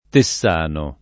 tessano [ te SS# no ]